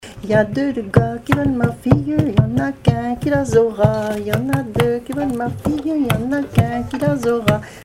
Divertissements d'adultes - Couplets à danser
danse : polka piquée
Pièces instrumentales à plusieurs violons